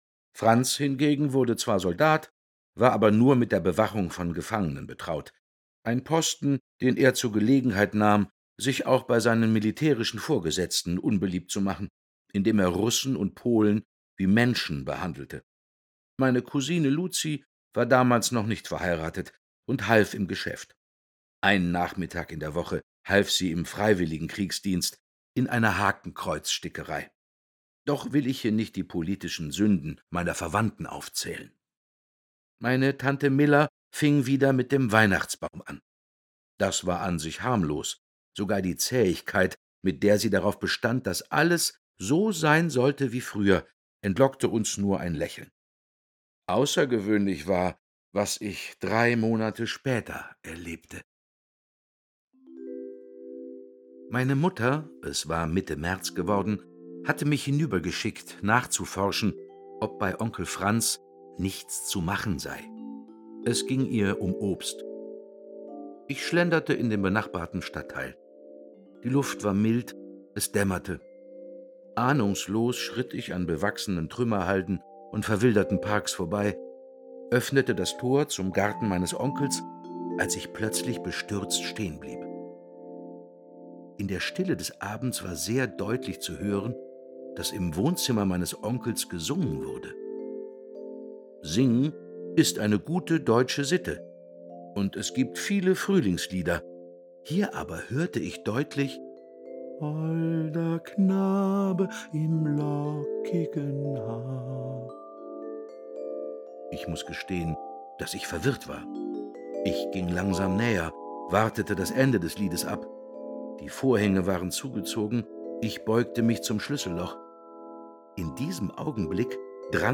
Konzertlesung nach Heinrich Böll
Dietmar Bär (Sprecher)